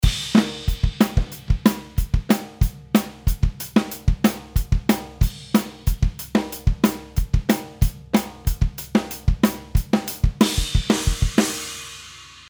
音の重心がグッと下がって奥行きが出てきましたね。